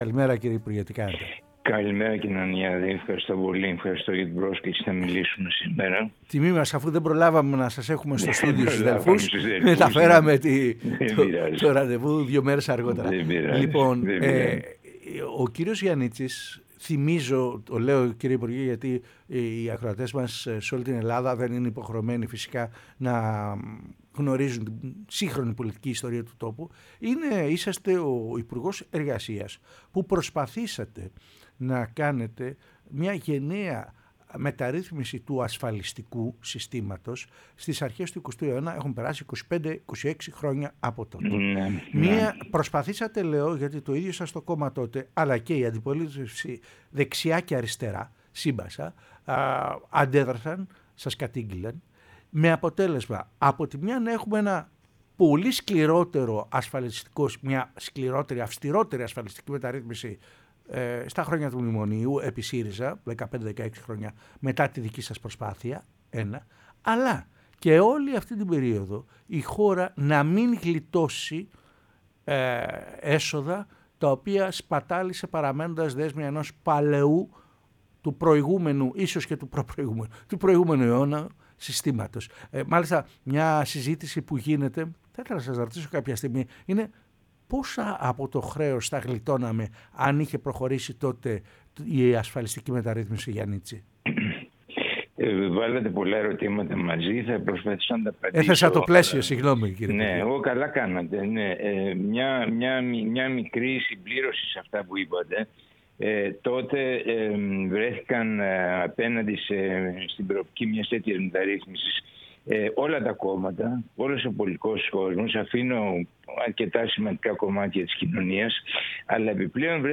Ο Τάσος Γιαννίτσης, Ομότιμος Καθηγητής του Τμήματος Οικονομικών Επιστημών του Εθνικού και Καποδιστριακού Πανεπιστημίου Αθηνών και πρώην Υπουργός, μίλησε στην εκπομπή “Κυριακή Μεσημέρι”